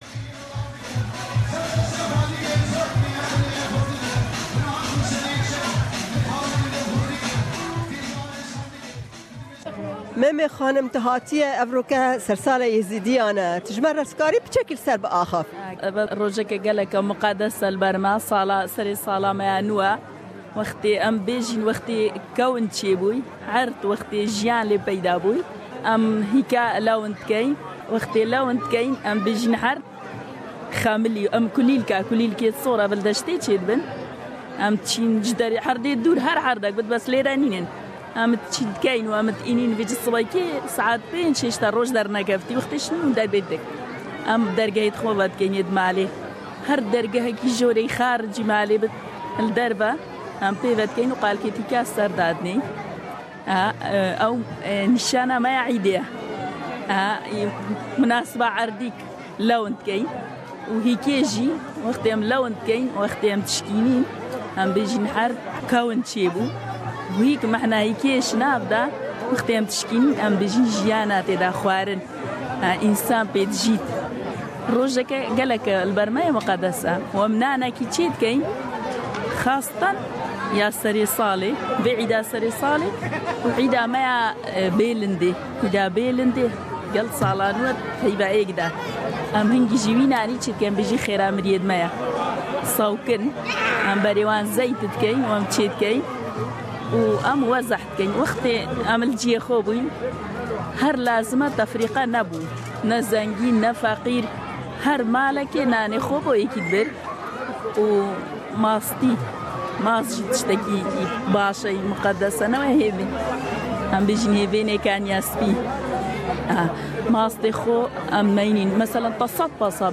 SBS Kurdish Program travelled to Wagga Wagga and spoke to members of the Yezidi community regarding the New Year.